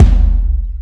Kick 7.wav